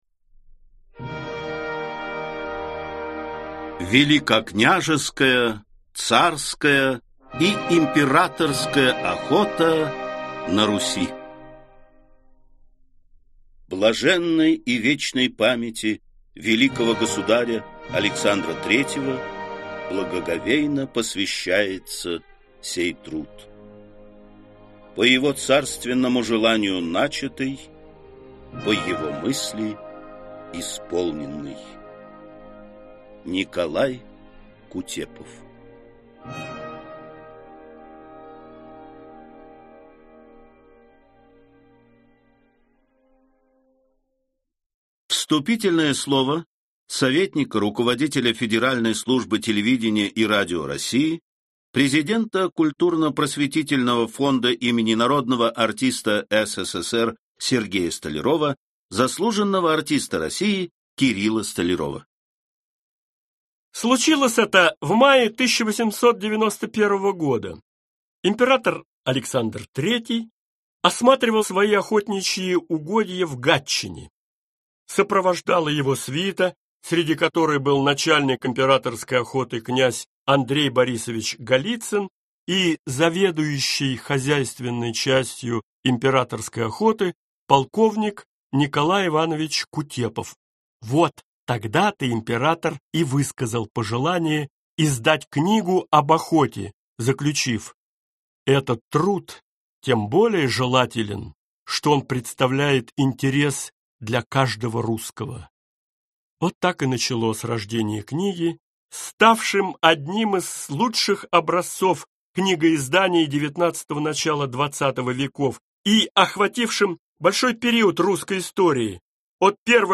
Аудиокнига Великокняжеская и царская охота на Руси с Х по XVI век | Библиотека аудиокниг
Aудиокнига Великокняжеская и царская охота на Руси с Х по XVI век Автор Николай Кутепов Читает аудиокнигу Кирилл Столяров.